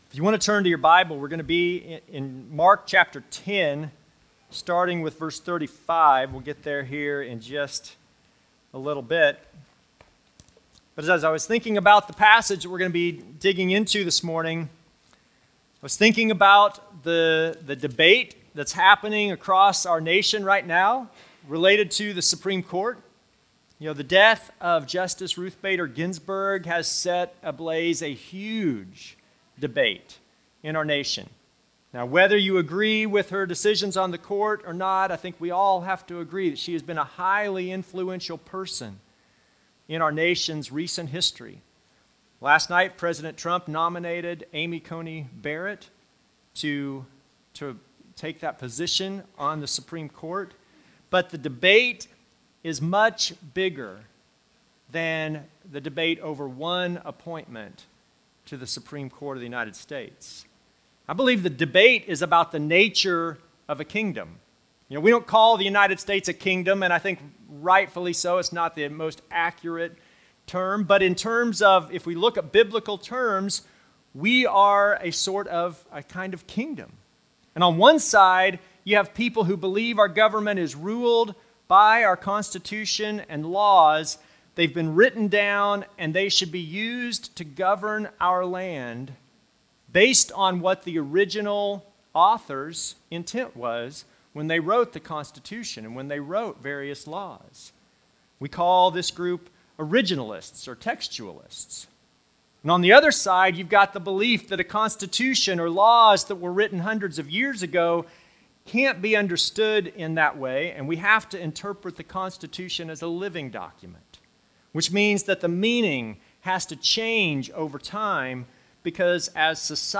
Passage: Mark 10:35-45 Service Type: Normal service